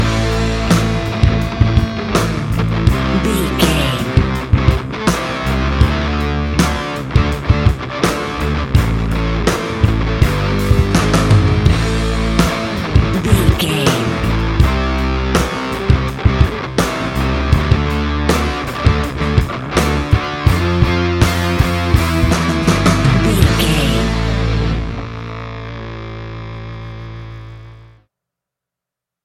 Ionian/Major
A♯
guitars
hard rock
heavy rock
distortion